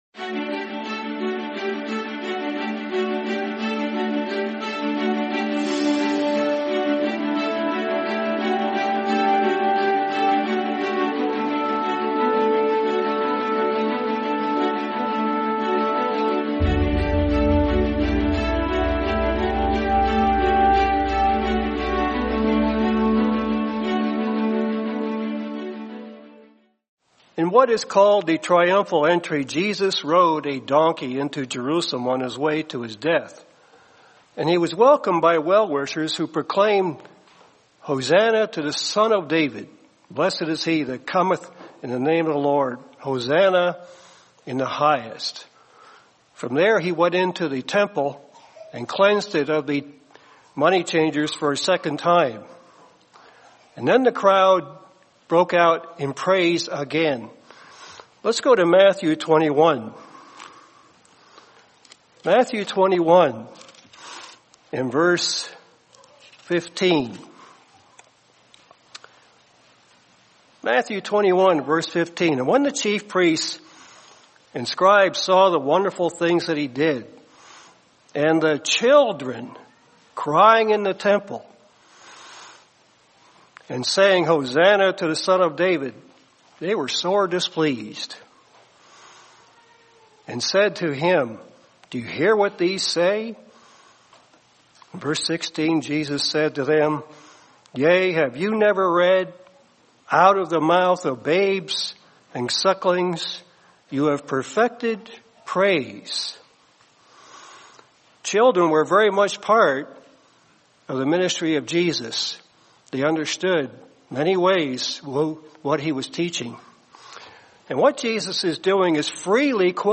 Faith of a Little Girl | Sermon | LCG Members